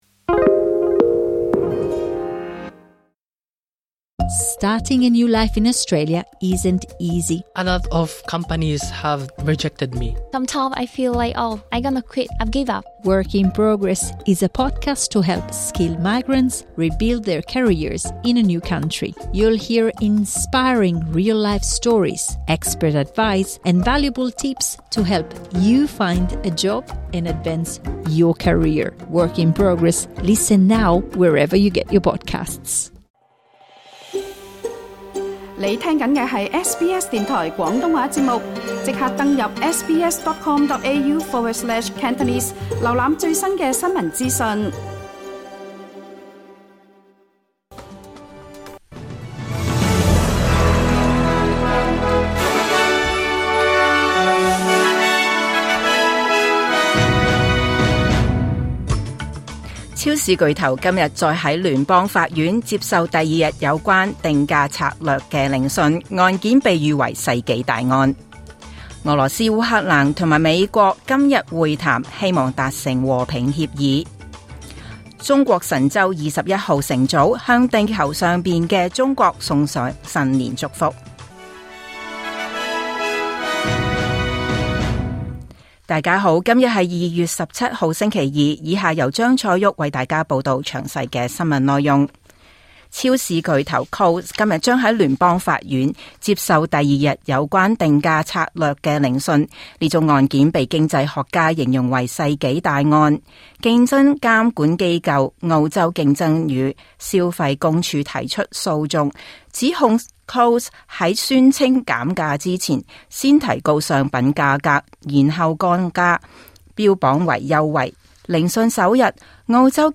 2026 年 2 月 17 日SBS廣東話節目九點半新聞報道。